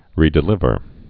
(rēdĭ-lĭvər)